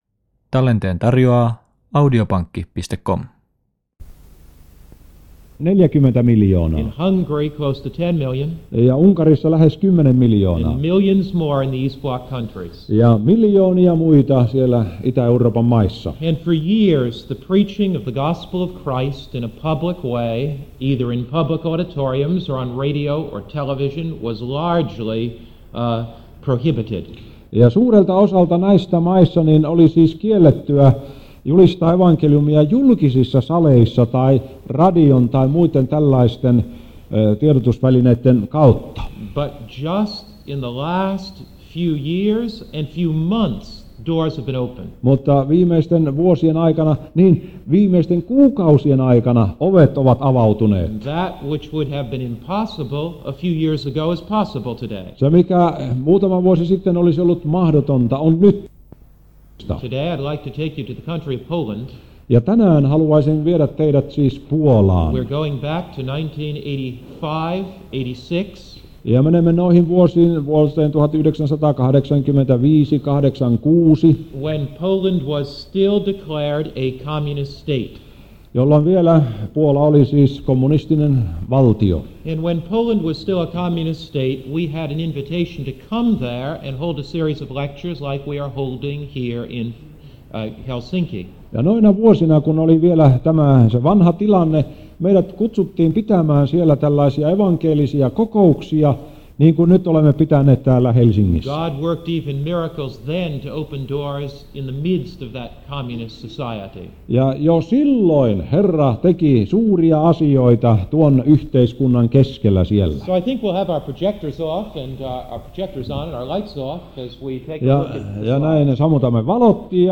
Helsingissä 1980-luvulla